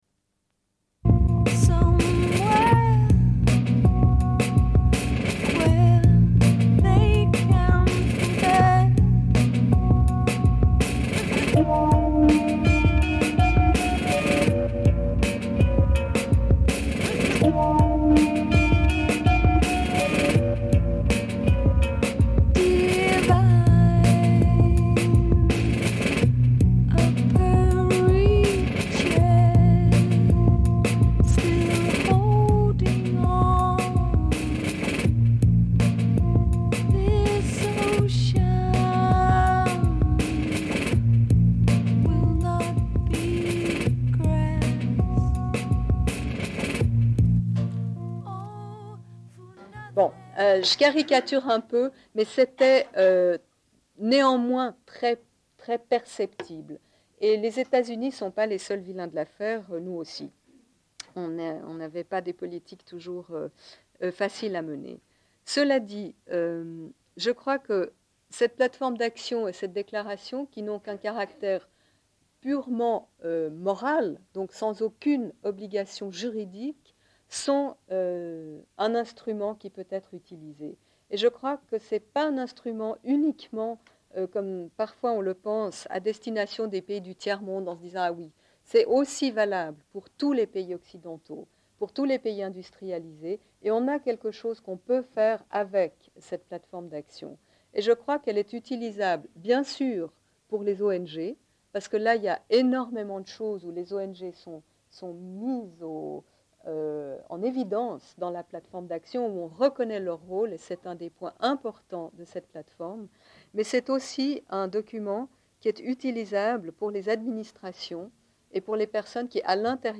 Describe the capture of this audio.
Une cassette audio, face B